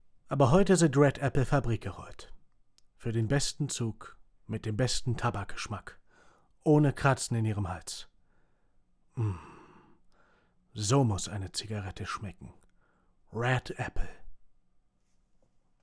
Neue Lüfter eingebaut - aber trotzdem ist ein Rauschen zu vernehmen
Ich frage, da ich leider nur neben dem PC aufnehmen kann.
alte Lüfter geschlossenes Gehäuse.wav
Bei den Aufnahmen höre ich bei beiden ein hochfrequentes Pfeifen.